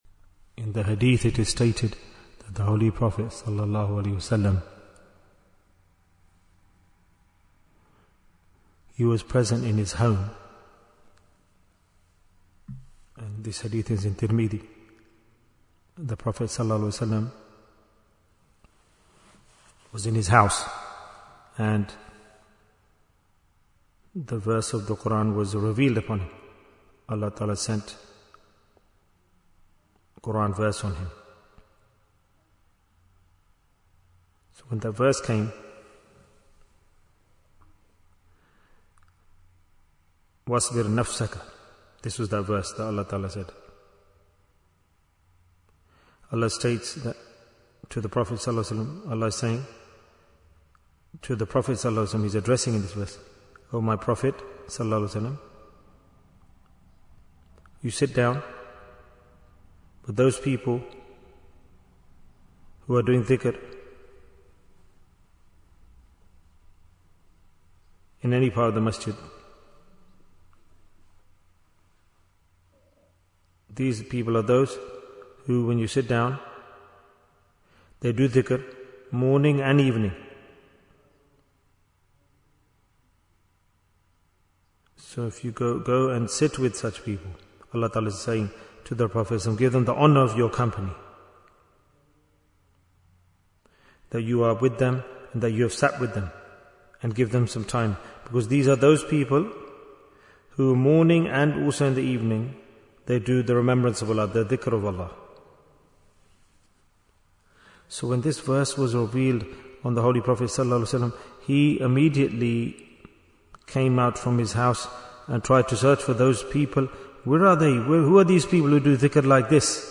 Jewels of Ramadhan 2026 - Episode 14 Bayan, 10 minutes24th February, 2026